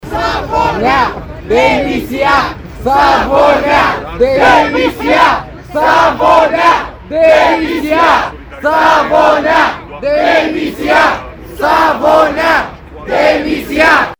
Câteva sute de oameni din cele trei orașe cer o justiție independentă, neacaparată de corupție. Ei scandează „Justiție, nu prescripție!”, „Justiție, nu corupție!”.